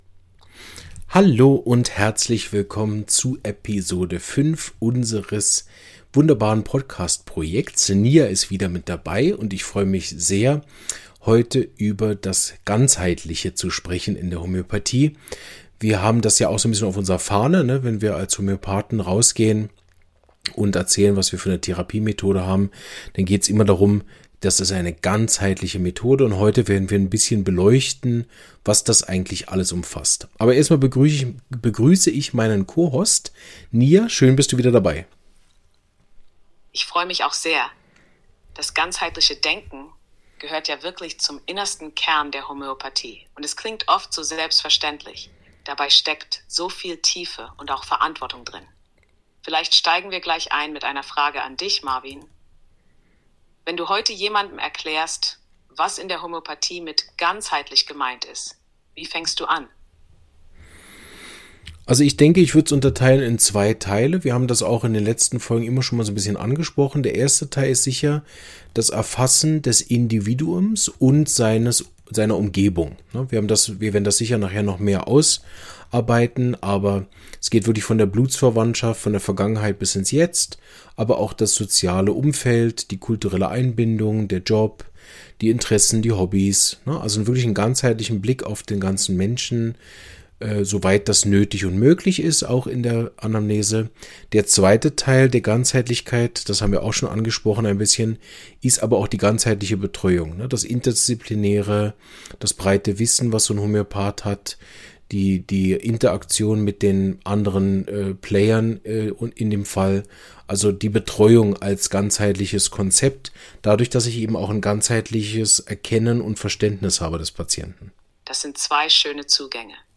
Den Menschen als Ganzes erfassen – Ganzheitliche Anamnese ~ Homöopathie erklärt – im Dialog mit einem KI-System Podcast